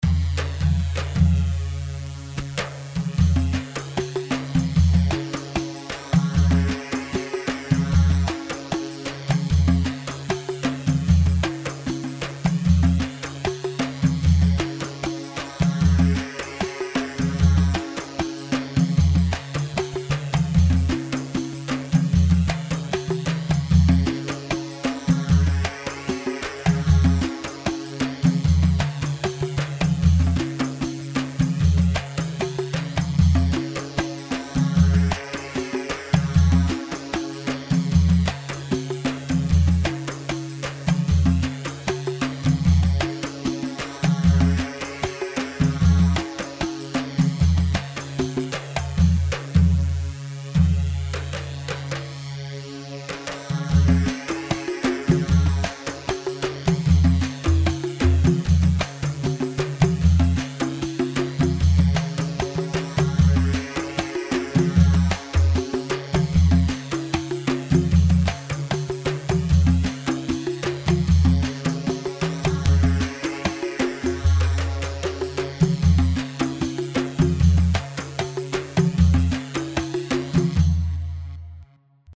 Bandari 2/4 76 بندري
Bandari-2-4-76-.mp3